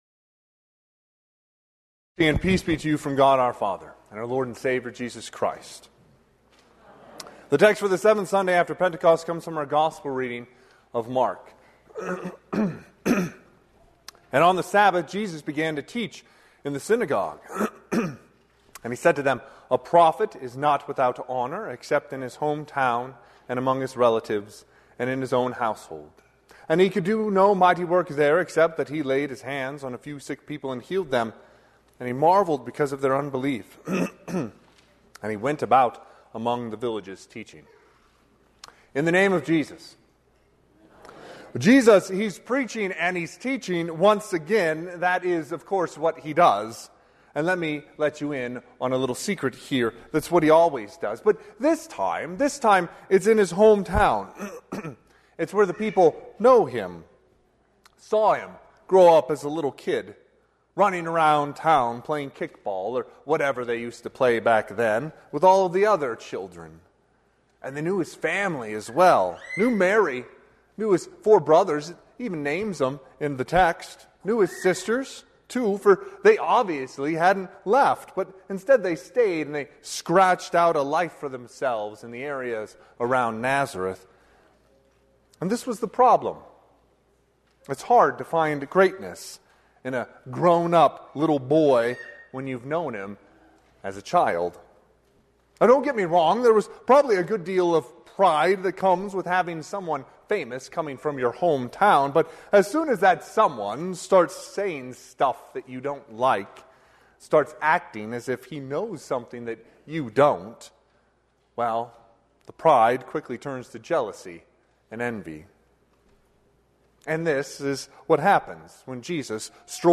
Sermon - 7/7/2024 - Wheat Ridge Lutheran Church, Wheat Ridge, Colorado